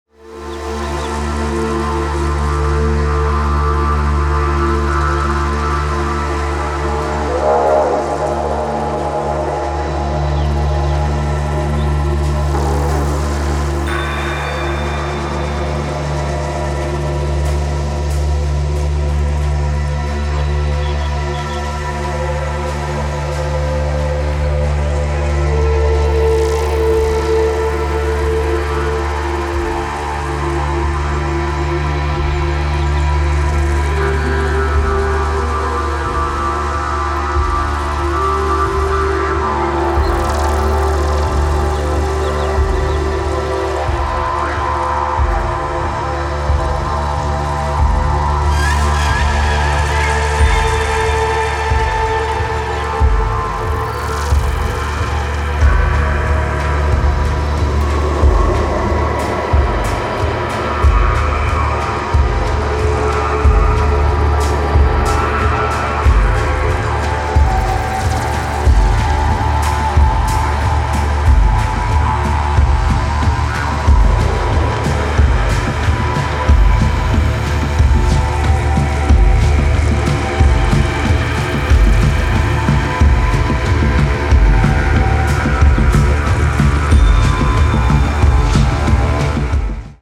ディジュリドゥによるロングドローンがなびくイントロが雰囲気たっぷりの92BPM